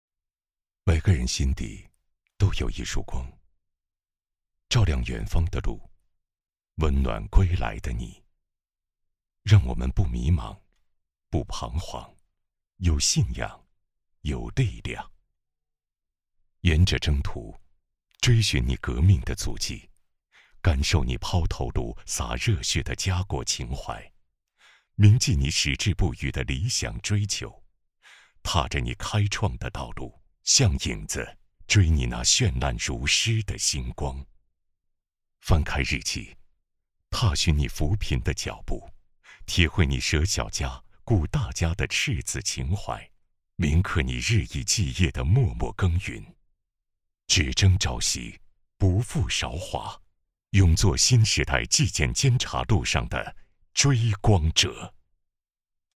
娓娓道来 公益爱心